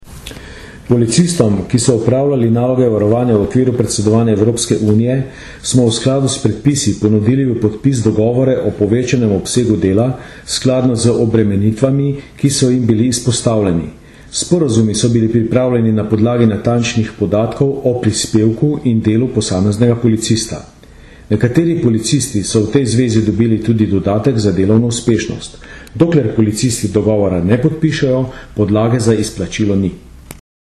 Tonski posnetek generalnega direktorja policije Jožeta Romška (mp3)